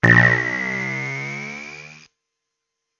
警报器鸣笛
描述：仓库报警，用变焦h4内置麦克风录制。
标签： 喇叭 安全 预警 警报 报警 紧急报警器
声道立体声